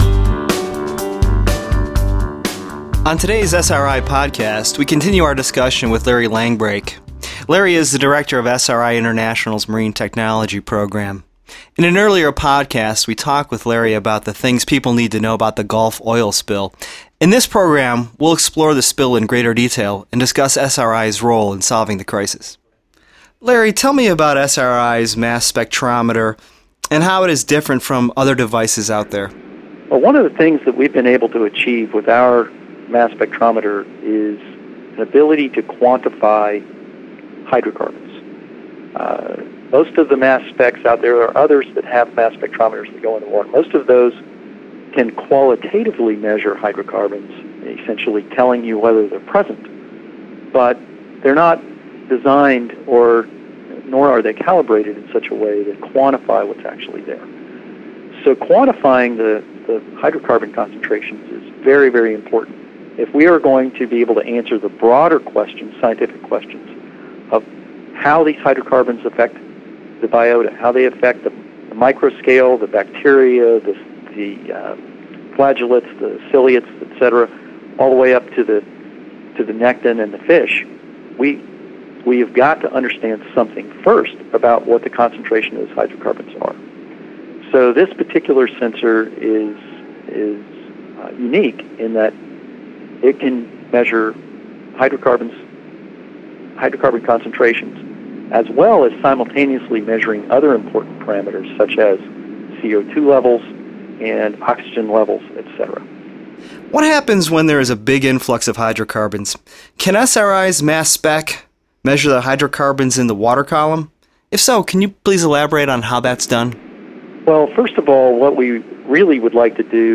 The SRI podcast series that I'm developing on behalf of the Silicon Valley-based R&D organization are being engineered and recorded at 2Bruce Studios in Asheville, NC.